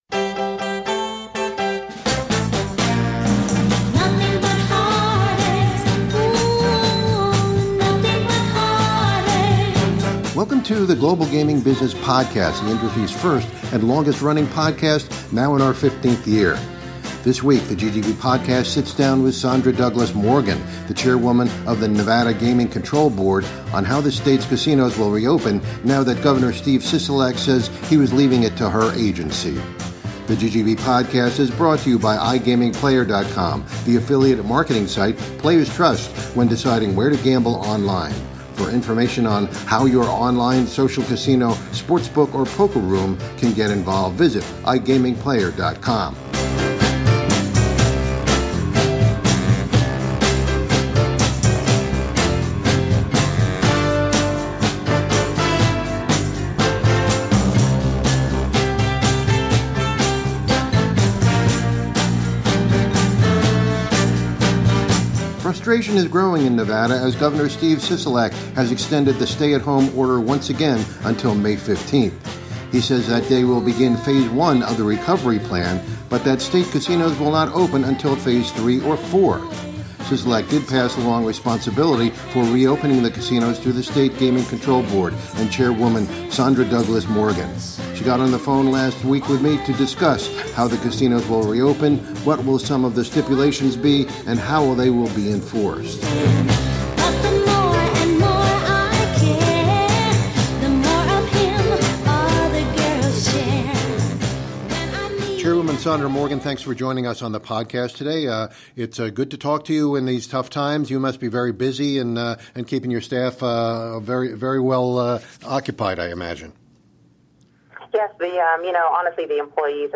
Chairwoman, Nevada Gaming Control Board…